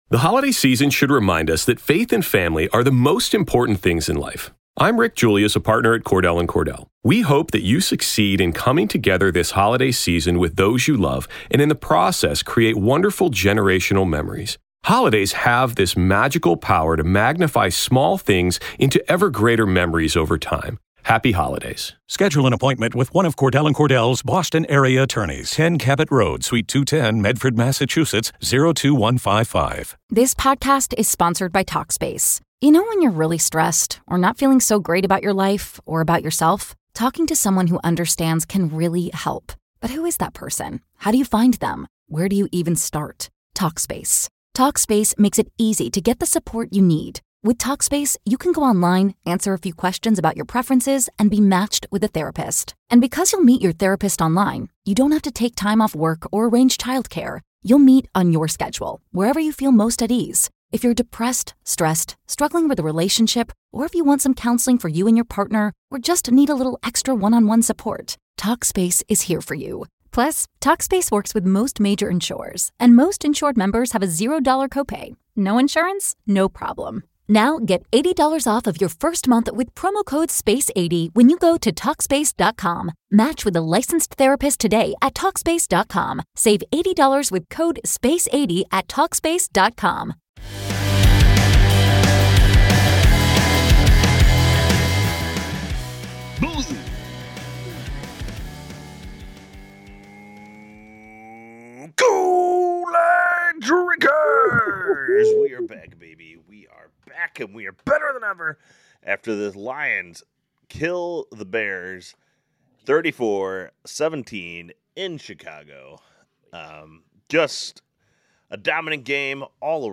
A few Lions fans discussing our favorite team as they prepare for greatness.